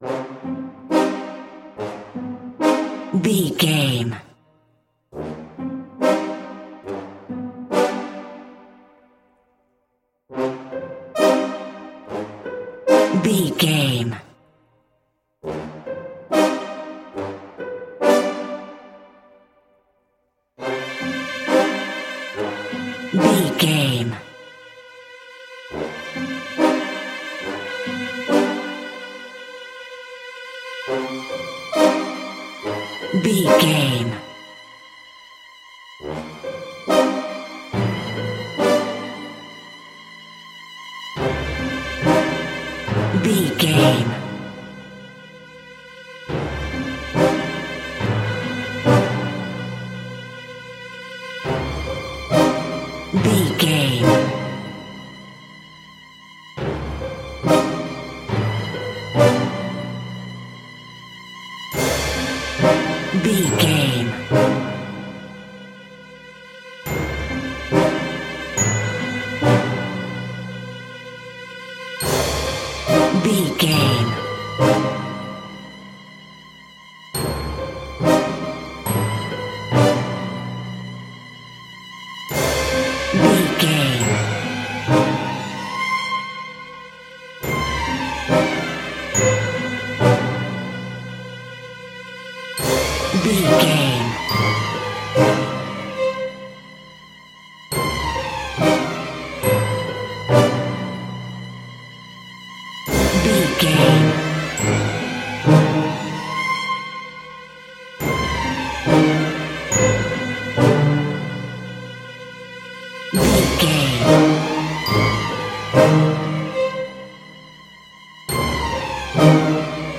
Aeolian/Minor
scary
ominous
dark
suspense
haunting
eerie
brass
strings
percussion
spooky
horror music
horror instrumentals